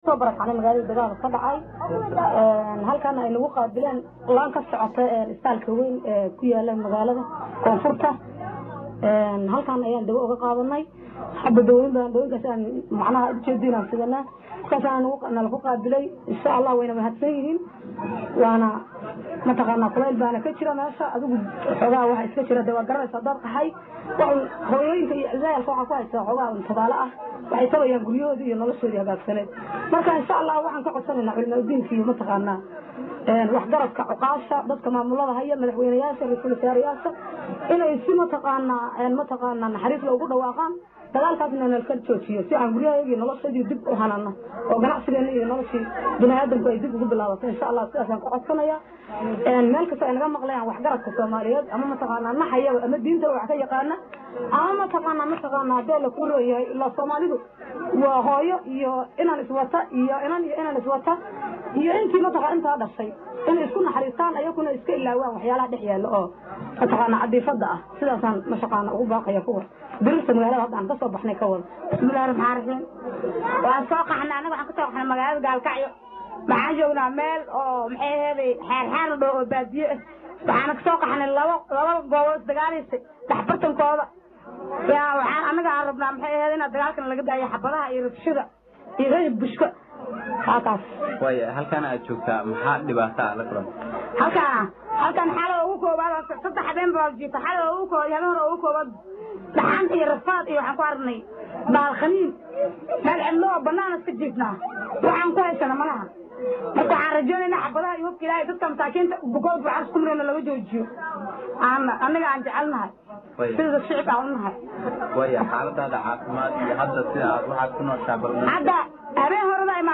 Qaar ka mid ah dadkii ka qaxay xaafadda Baraxlay ee magaalada Gaalkacayo ayaa markii ugu horaysay Saxaafadda uga Waramay xaaladda nololeed ee ay ku sugan yihiin mudadii shanta maalmood ahayd ee ay qaxa ku jireen.